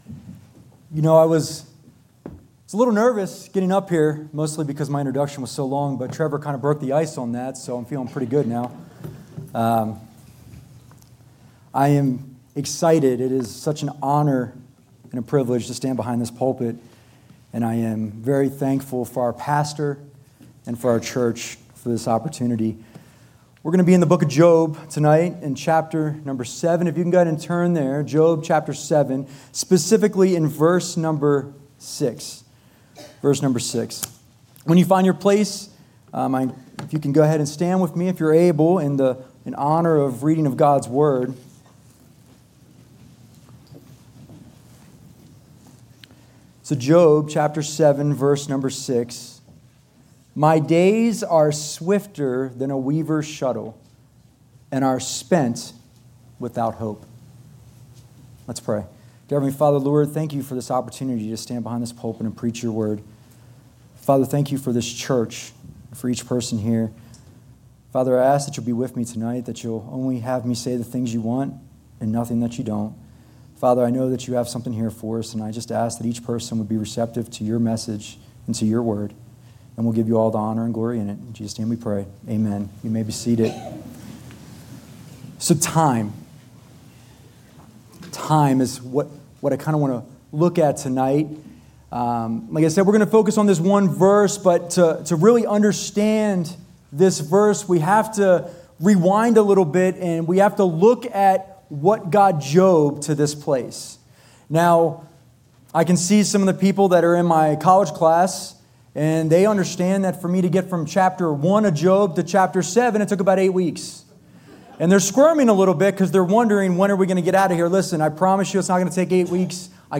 " Guest & Staff Preachers " Guest & Staff Preachers at Bethany Baptist Church Scripture References: Job 7:6